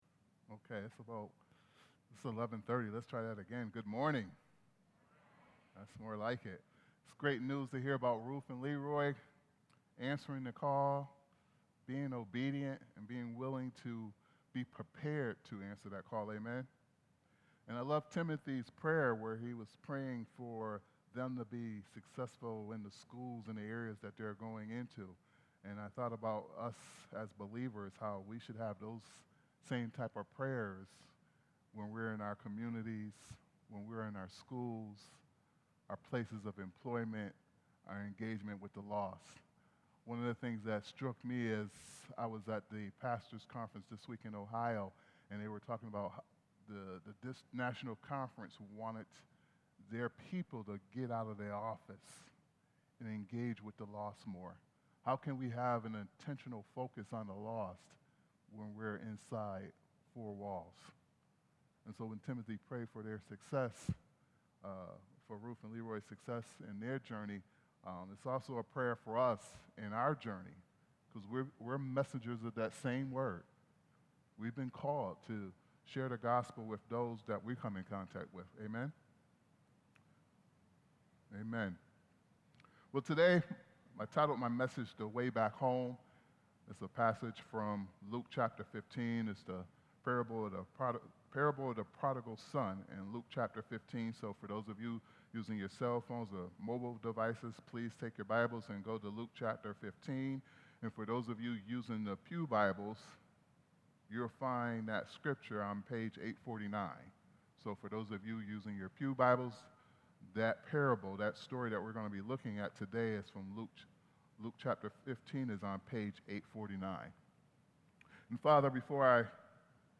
A message from the series "Not part of Series."